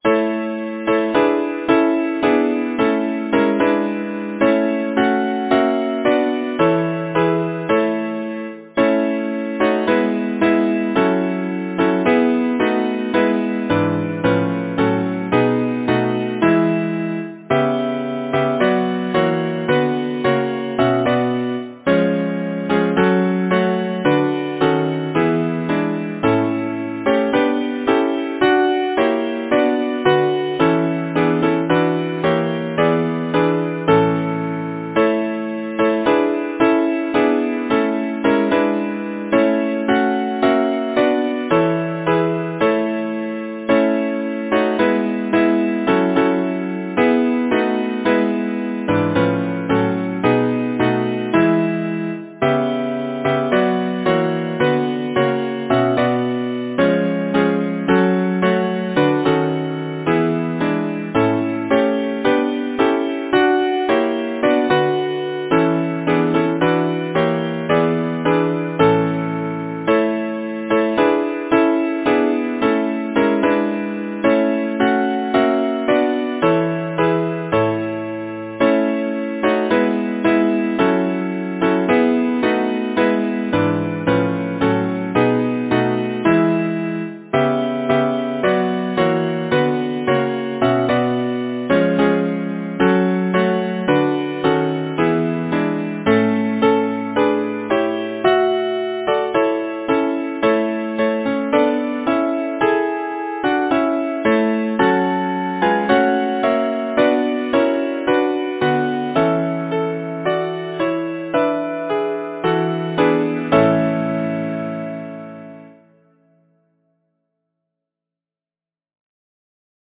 Joseph Samuel Lavies Number of voices: 4vv Voicing: SATB Genre: Secular, Partsong
Language: English Instruments: A cappella